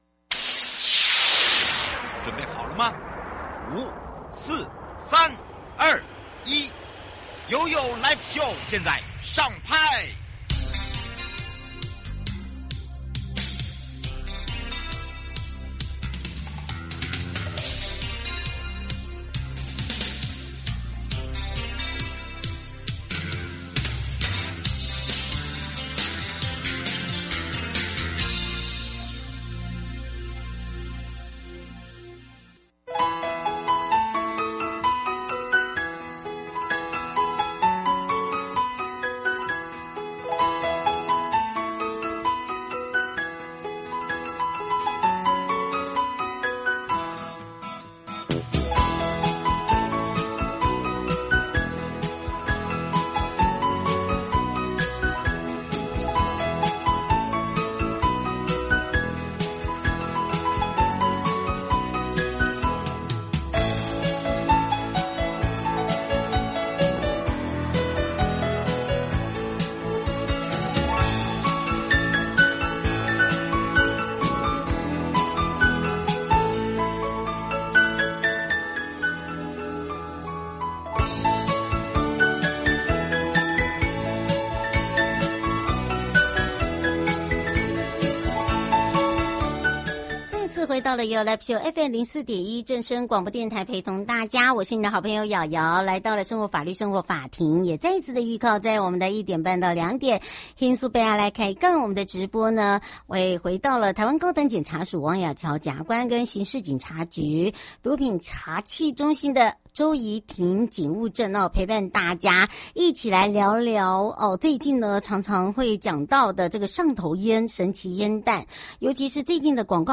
法務部鄭銘謙部長 3.法務部 節目內容： 1.